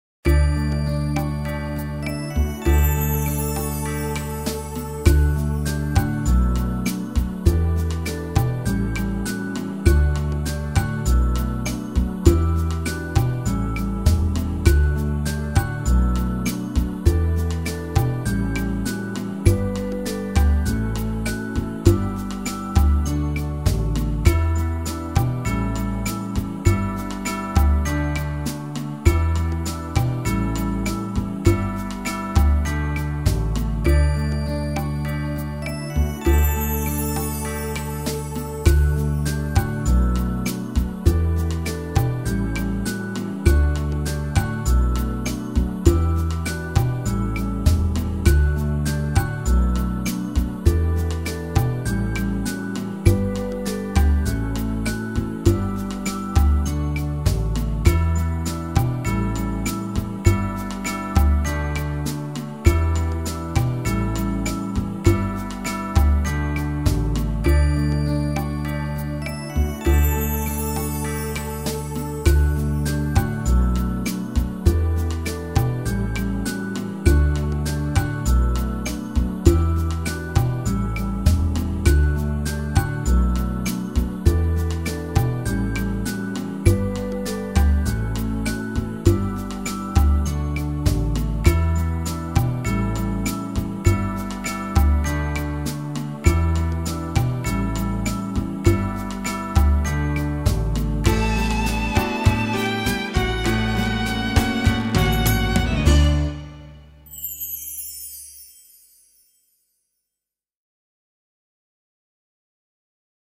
instrumentalnej do samodzielnej nauki);
01_Ścieżka_1.mp3 1.76MB Piosenka rekrutacyjna "Zegary"- podkład muzyczny